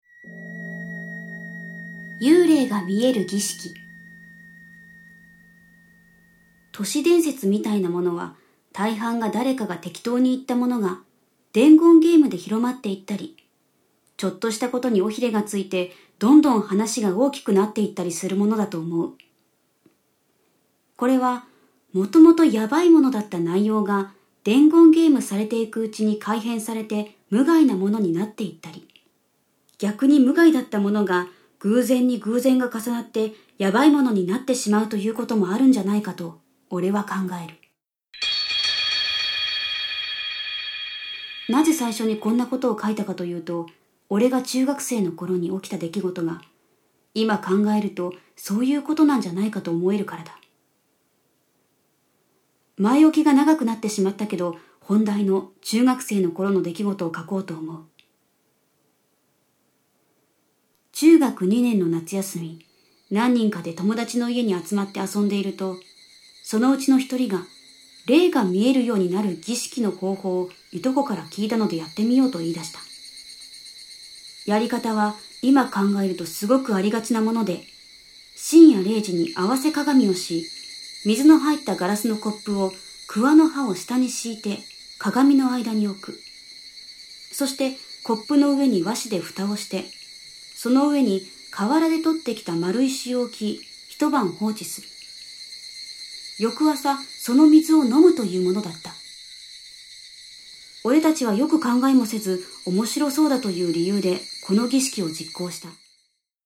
SEにもこだわり、最先端技術を駆使し、擬似的に3D音響空間を再現、格別の臨場感を体感出来ます！